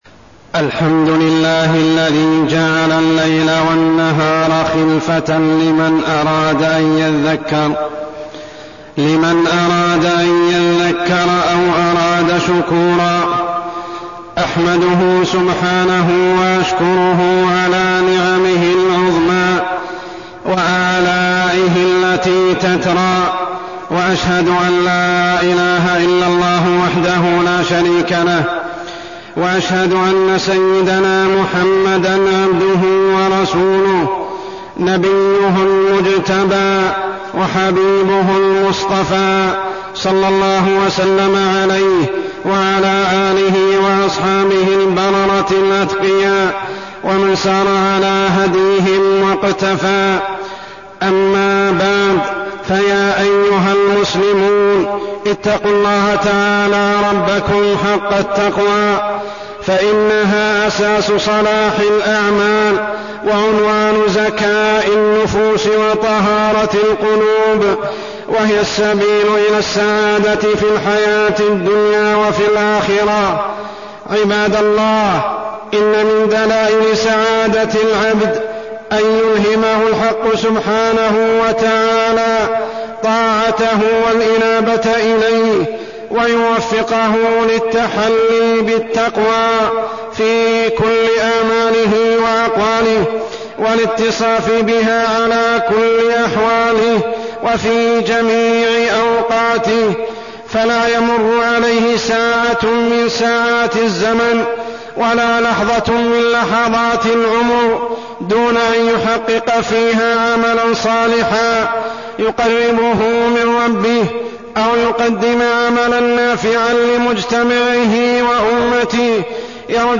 تاريخ النشر ١٩ صفر ١٤١٧ هـ المكان: المسجد الحرام الشيخ: عمر السبيل عمر السبيل أهمية الوقت